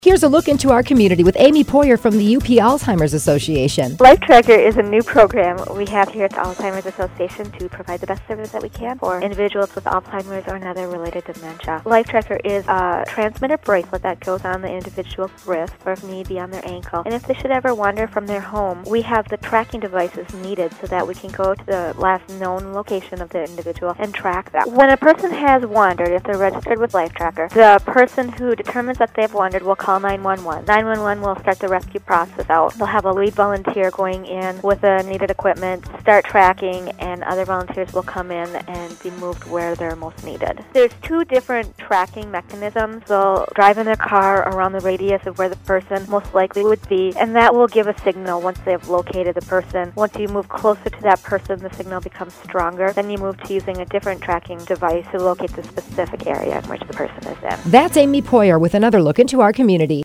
The cost is $8.50 for adults and $4 for children under seven Click HERE To Listen To Interview as Mp3 Click...